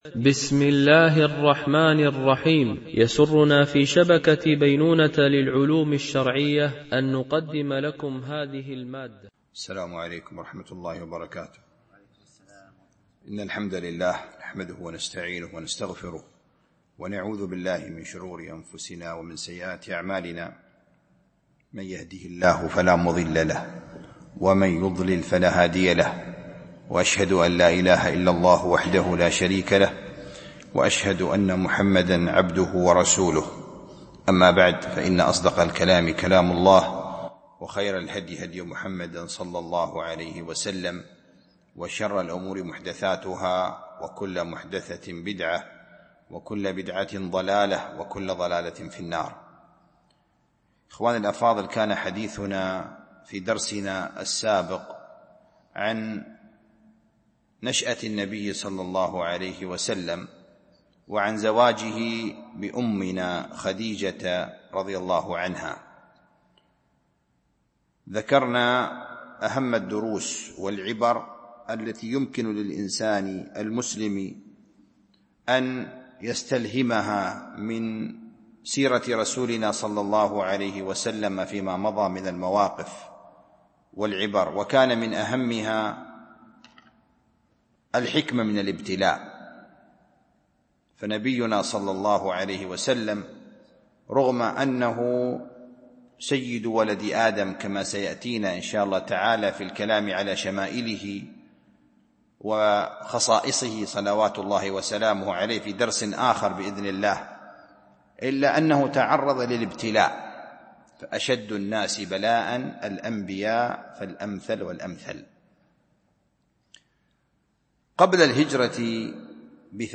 الدروس والعبر من سيرة خير البشر ـ الدرس 5 ( زوجات النبي ﷺ )
MP3 Mono 22kHz 32Kbps (CBR)